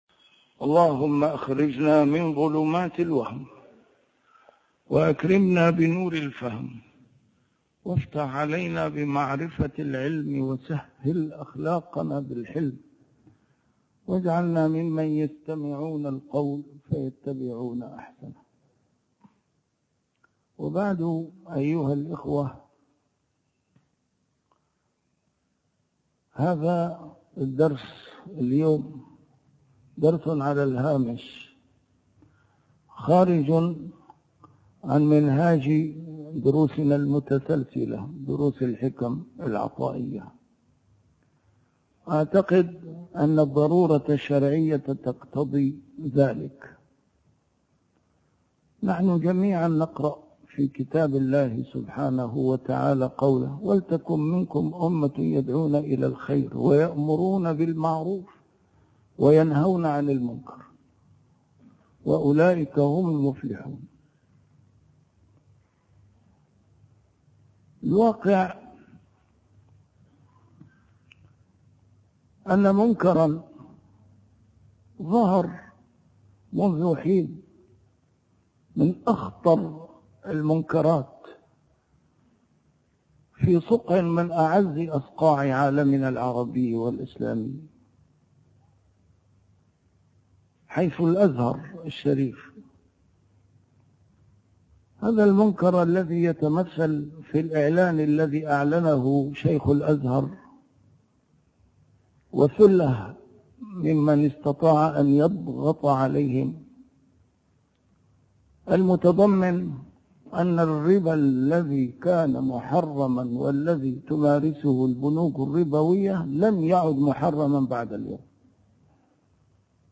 A MARTYR SCHOLAR: IMAM MUHAMMAD SAEED RAMADAN AL-BOUTI - الدروس العلمية - محاضرات متفرقة في مناسبات مختلفة - حكم الفوائد الربوية